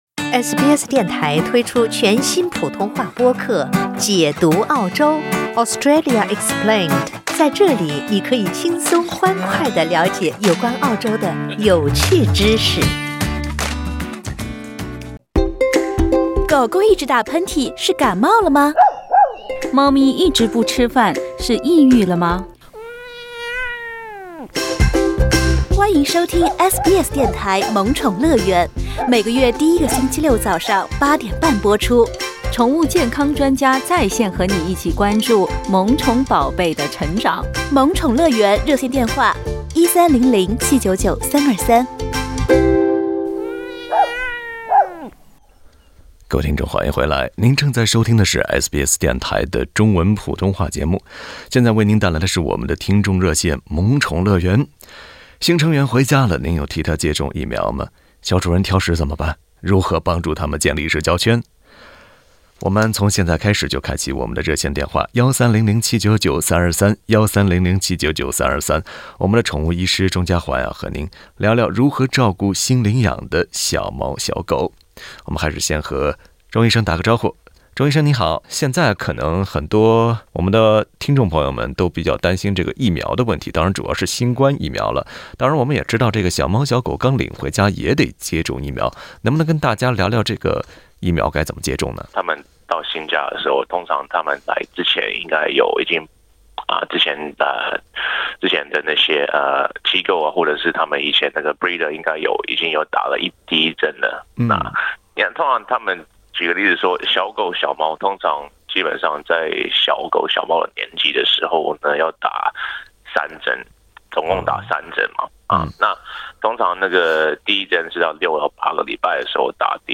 （歡迎您點擊圖片音頻，收聽完整寀訪）